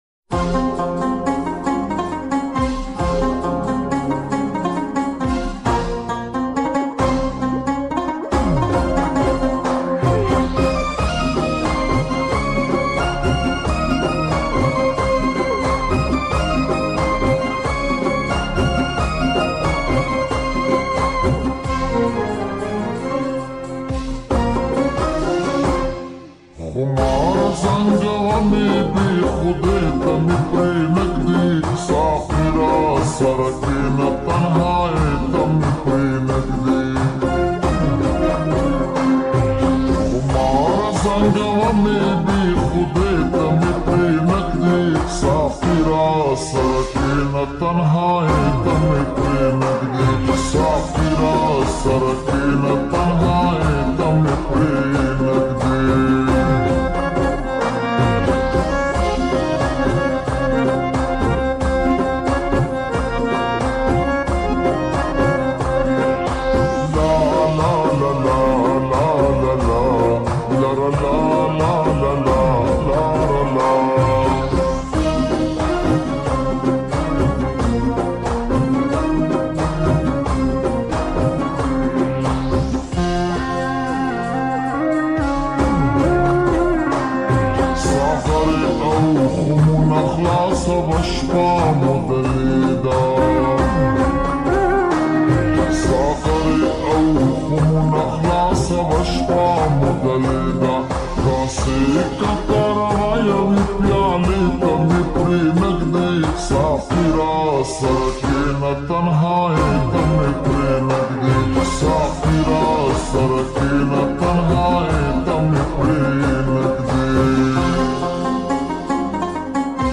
pashto full song